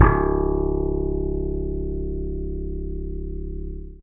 55v-bse05-c#2.aif